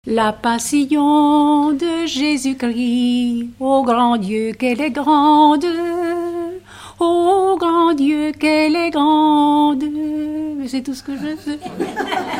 premier couplet seul
Regroupement de chanteurs du canton
Pièce musicale inédite